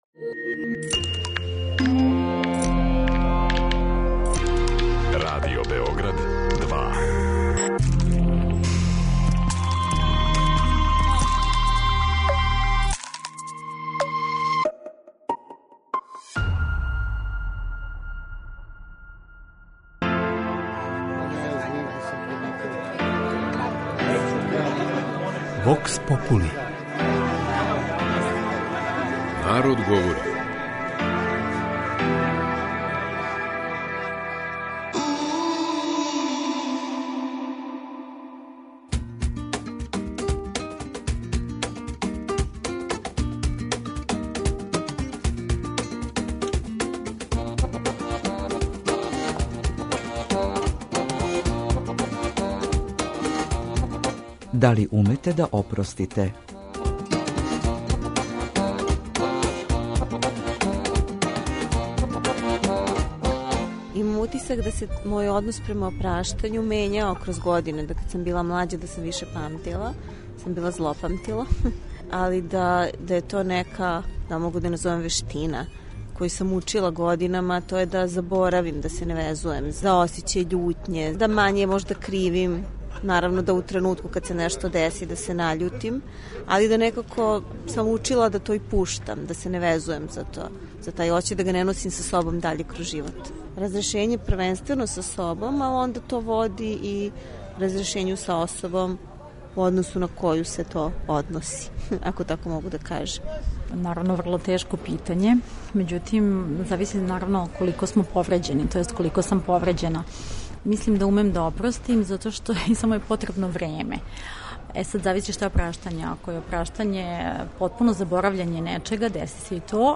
Како се у нашој кутлури односимо према чину опраштања? Питали смо наше суграђане да ли умеју да опросте.
Вокс попули